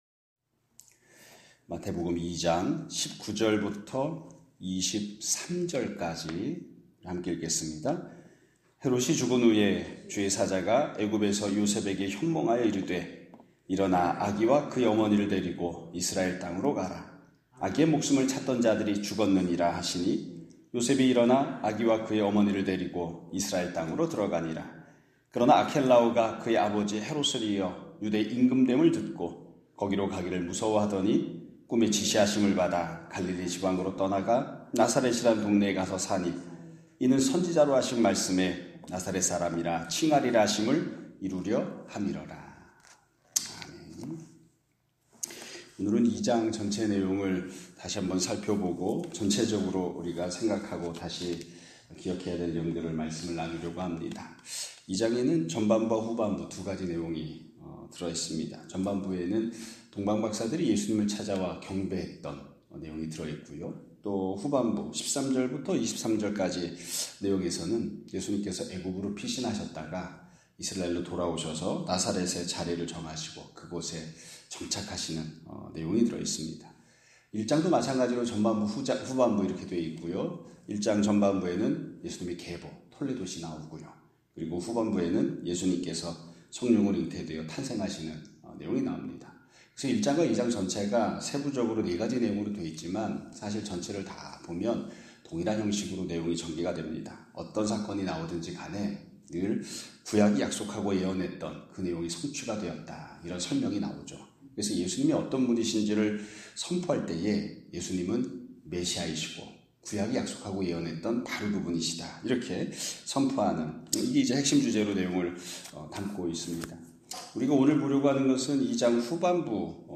2025년 4월 일(수 요일) <아침예배> 설교입니다.